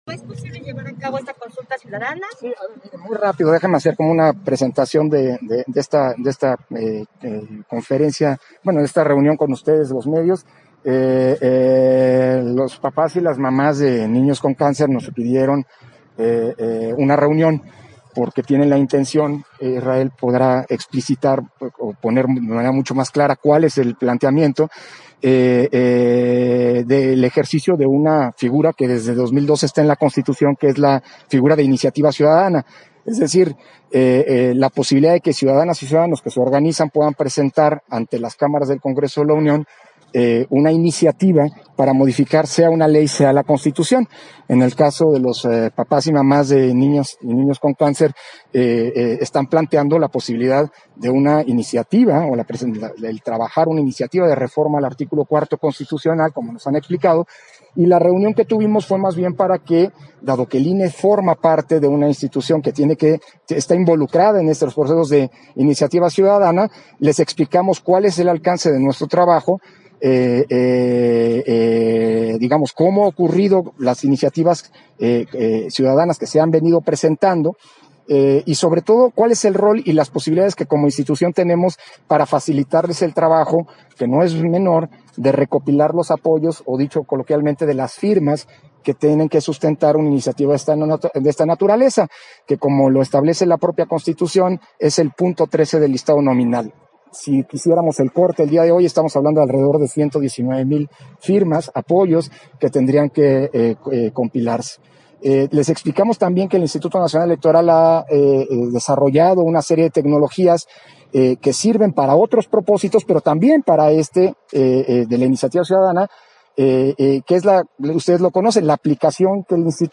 Versión estenográfica de la entrevista a Lorenzo Córdova, al término de la reunión con los papás de niñas/os con cáncer